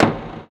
Closed Hats
07_Perc_19_SP.wav